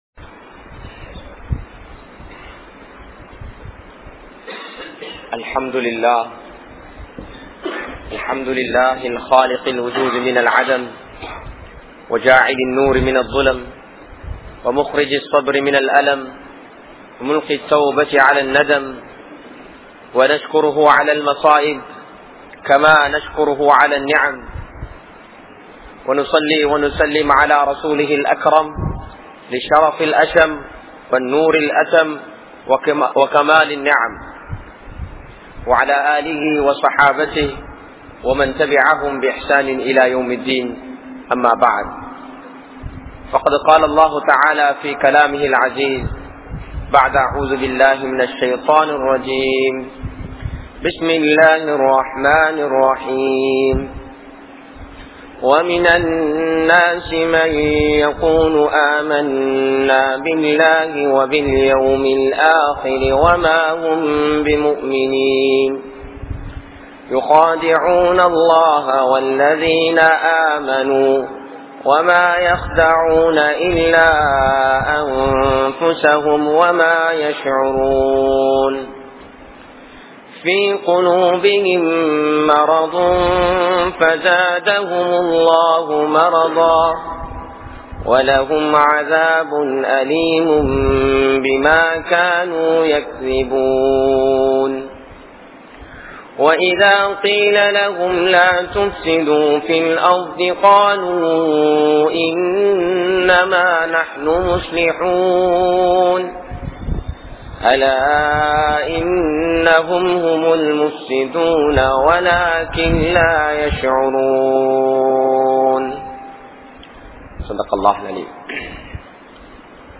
Yaar Mu`min? Yaar Munafiq? | Audio Bayans | All Ceylon Muslim Youth Community | Addalaichenai